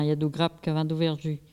collecte de locutions vernaculaires